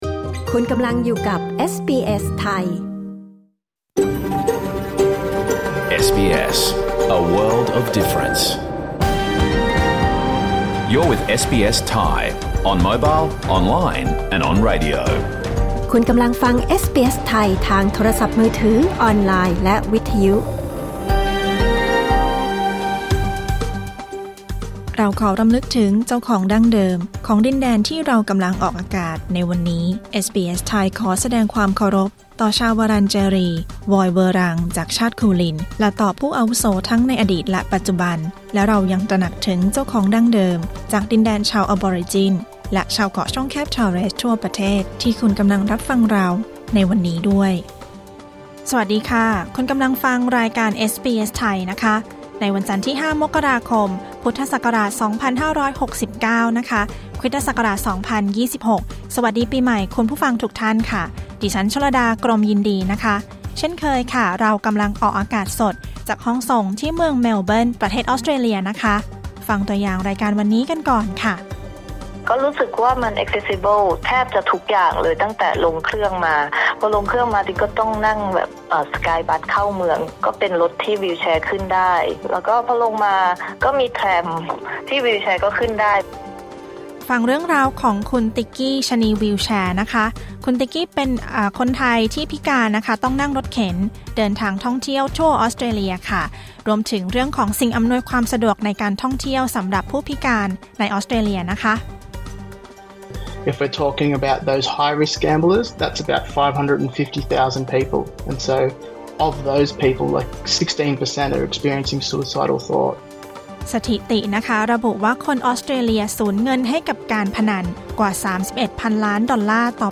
รายการสด 5 มกราคม 2569